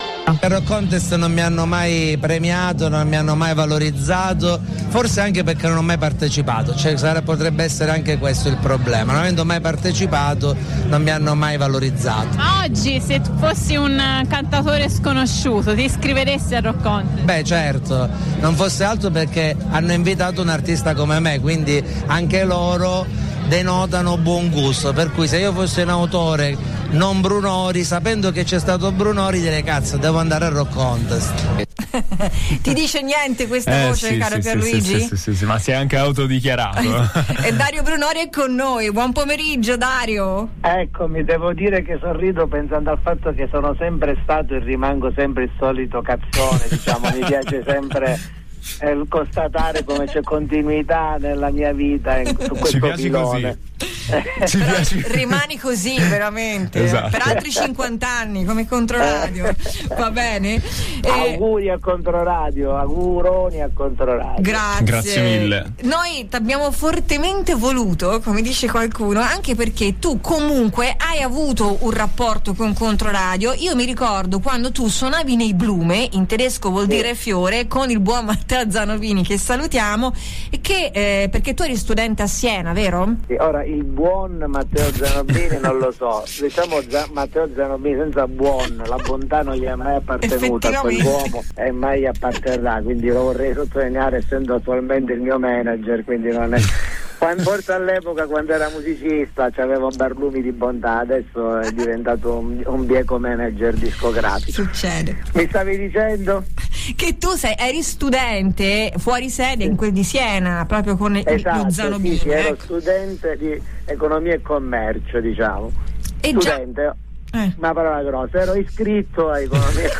Dario Brunori e Controradio, un’amicizia lunga 20 anni. Ascolta l’intervista.